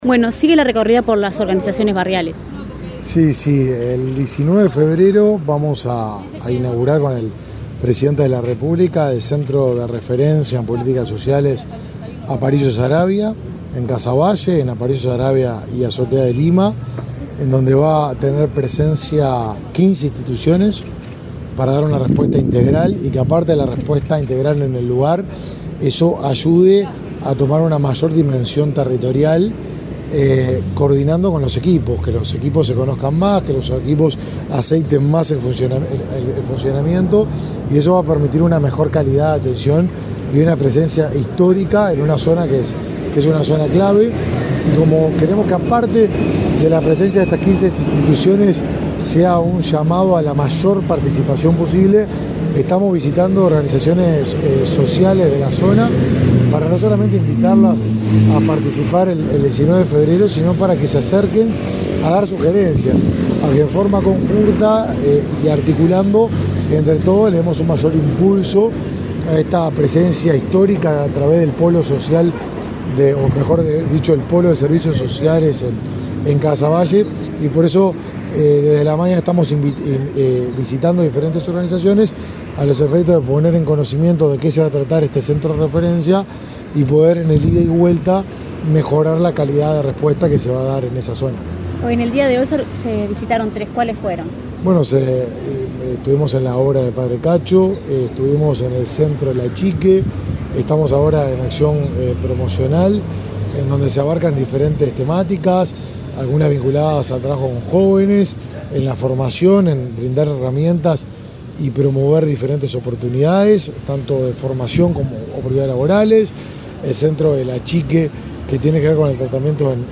Declaraciones del ministro de Desarrollo Social, Martín Lema
El ministro de Desarrollo Social, Martín Lema, dialogó con la prensa durante una recorrida que realizó, este jueves 4, por varias zonas de Montevideo.